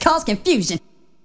VOX SHORTS-2 0010.wav